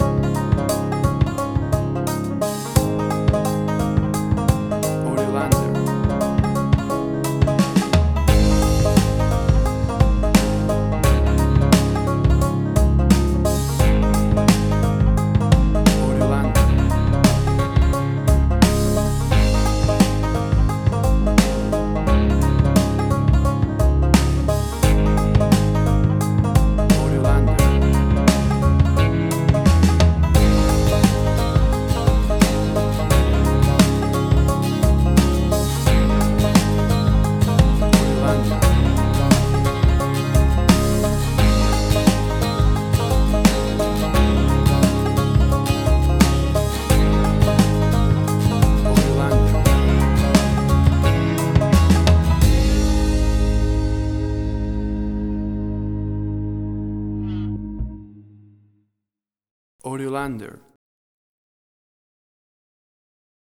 great for fun upbeat country music parties and line dances.
WAV Sample Rate: 16-Bit stereo, 44.1 kHz
Tempo (BPM): 87